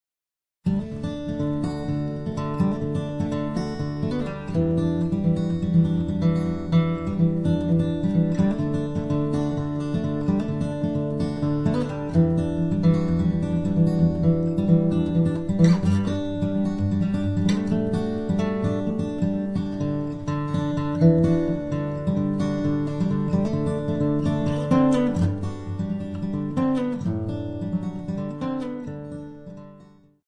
fingerstyle guitar solos on 6 and 12 string guitars
solos on 6 and 12 strings, as well as ukulele